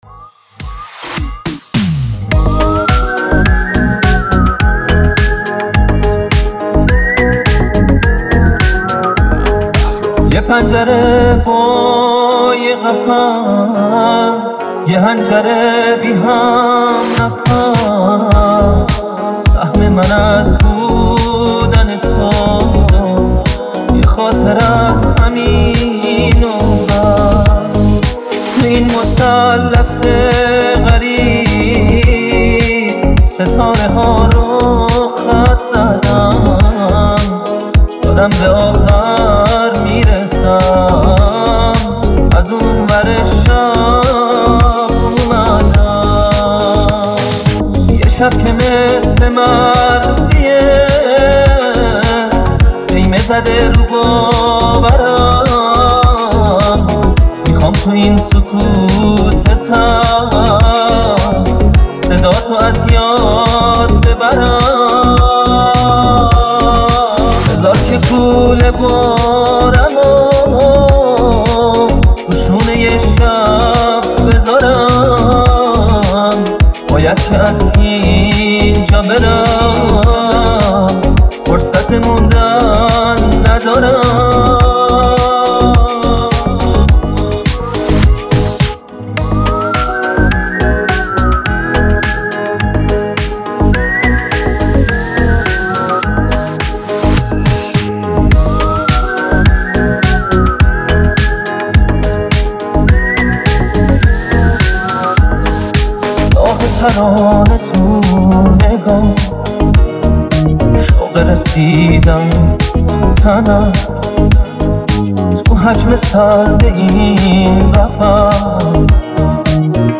ریمیکس خوبیه :/ ولی هنوزم کلّی غم داره این عاهنگ